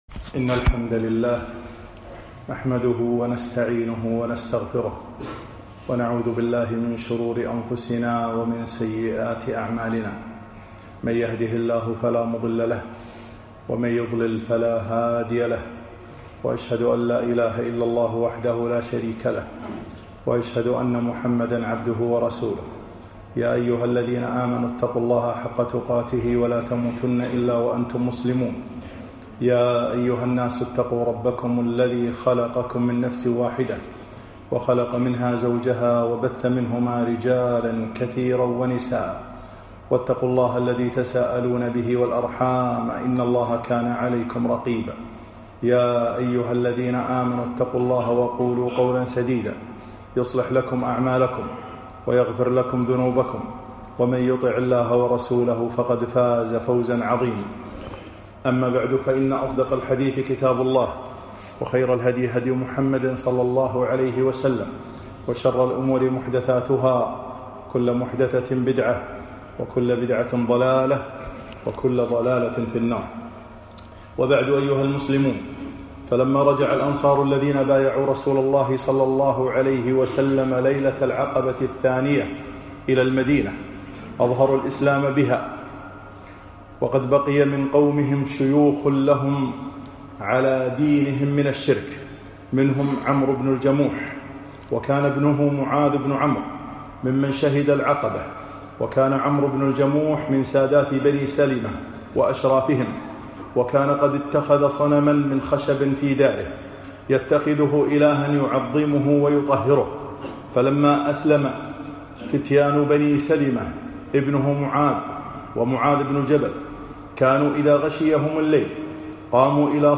خطب السيرة النبوية 10